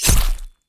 blade_hit_08.wav